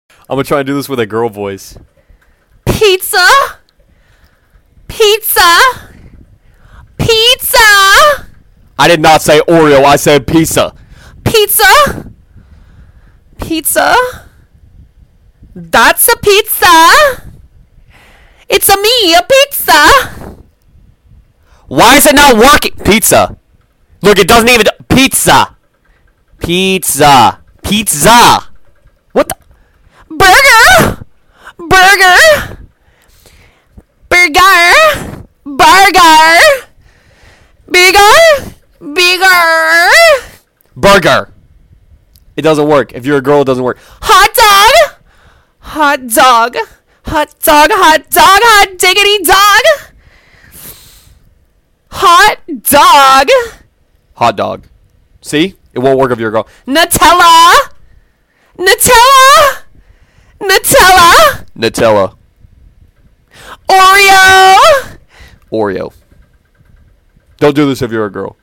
It doesnt work with a girl voice